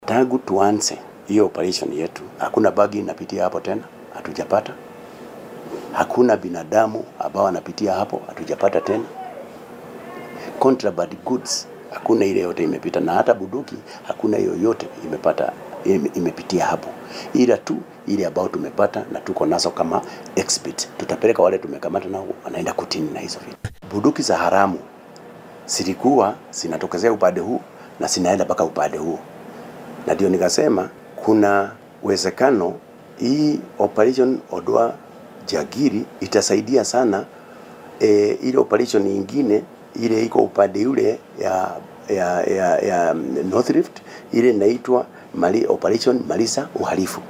Taliyaha guud ee booliiska dalka Douglas Kanja oo warbaahinta faahfaahin ka siiyay sida uu howlgalka u socdo ayaa sheegay in ay ku guulaysteen ciribtirka daroogooyiinka iyo hubka sharci darrada oo la marin jiray waddada weyn ee Isiolo iyo Marsabit xiriiriso. Kanja ayaa sidoo kale xusay in ay gacanta ku soo dhigeen saraakiil sare ee OLA oo haatan la sugaayo in maxkamadda la soo taago.